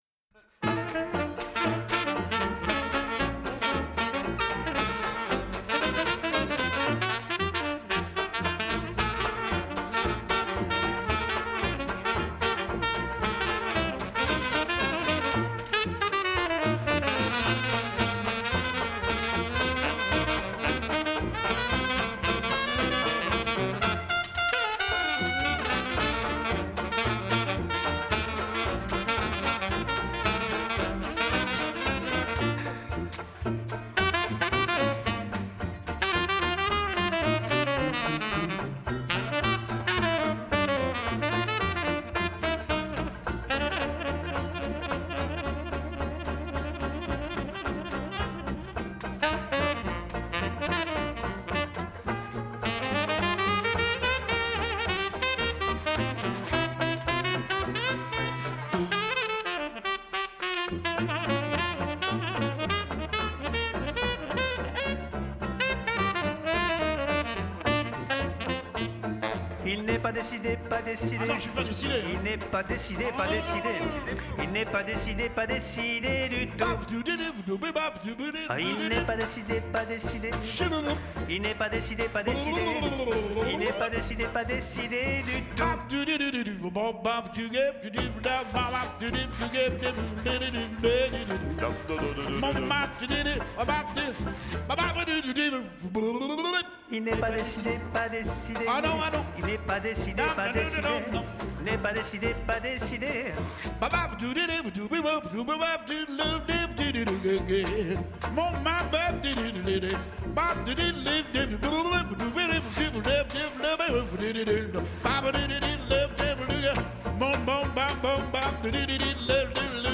Les thèmes qui composent notre répertoire sont des standards de la musique Nouvelle Orléans, émaillés de clins d'oeil à la variété Française ( Brassens, Nougaro, Trénet...).